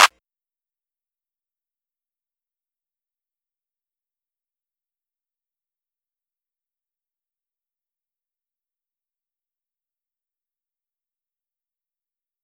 clap 1.wav